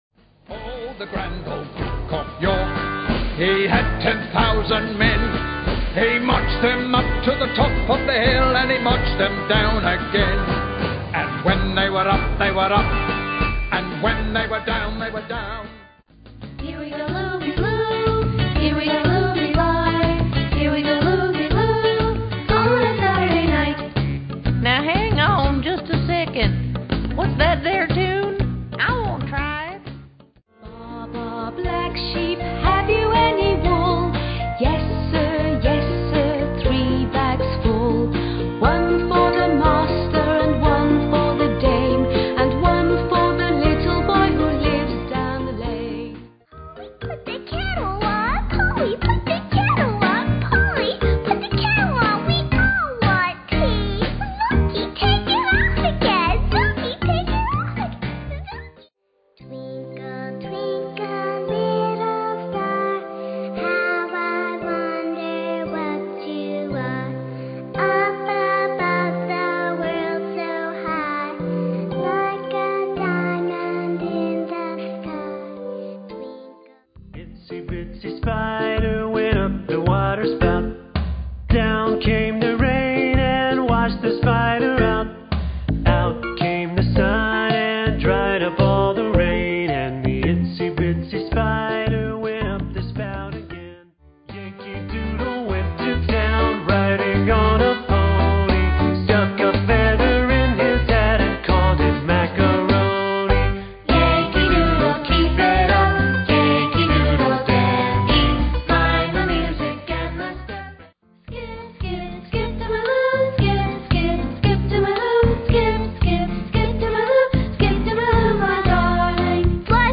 30 popular kids' songs with delightful arrangements!